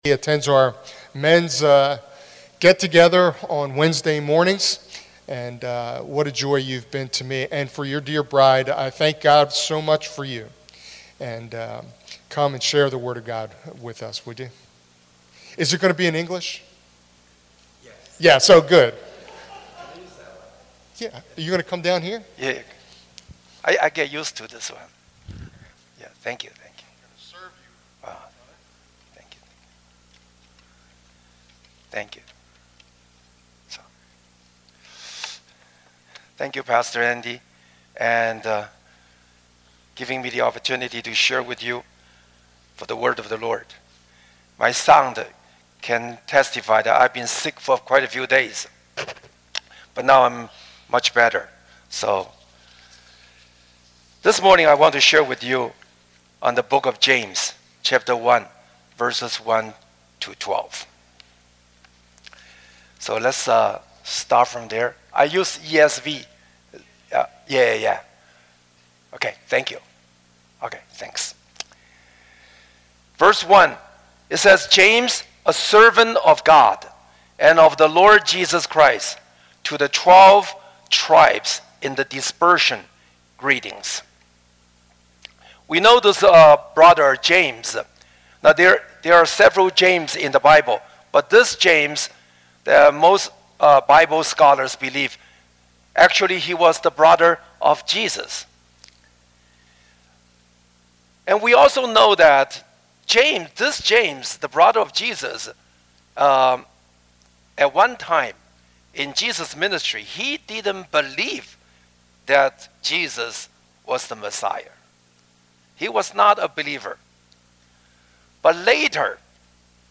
A message from the series "Messages."
Sunday Morning teaching archive from Windsor Chapel in Princeton Junction, NJ.